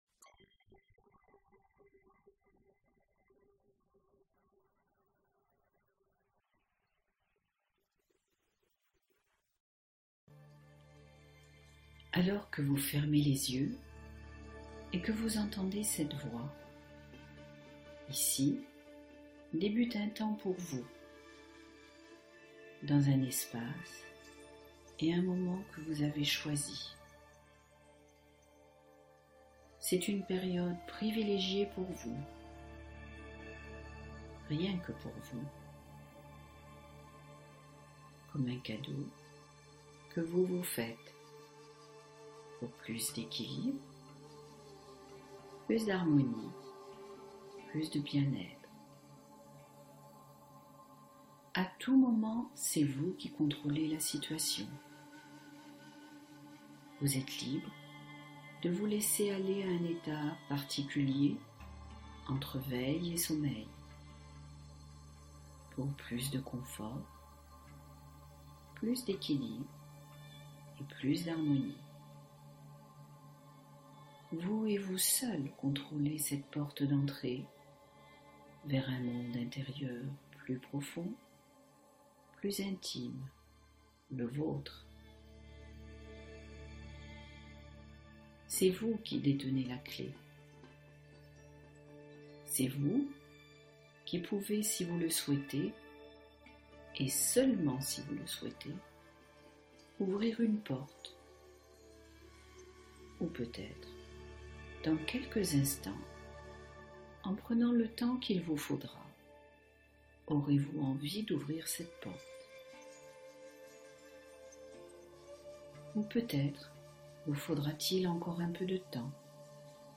relaxation guidée programmante